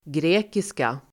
Uttal: [gr'e:kiska]
grekiska.mp3